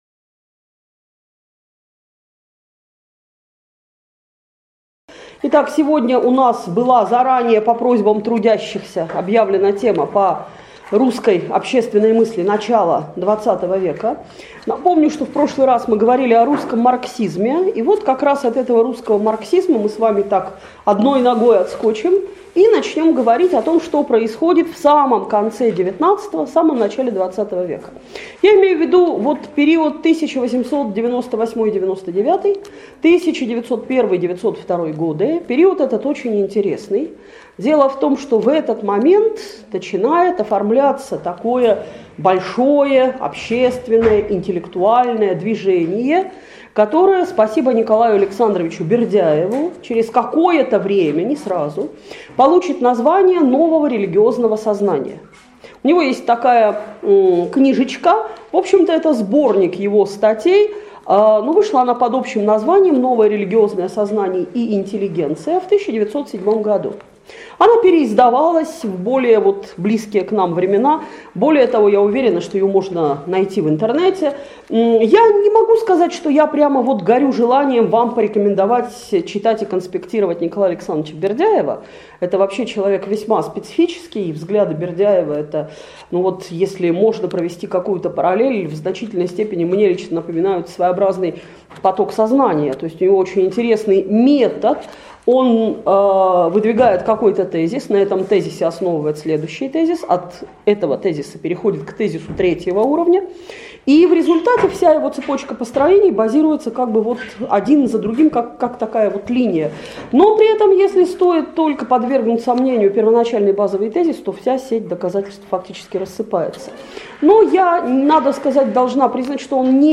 Лекция раскрывает основные этапы развития русской общественной мысли на кануне революционных потрясений. Рассмотрены портреты ее ярких представителей и даны характеристики таким явлениям, как богостроительство, богоискательство и русский космизм.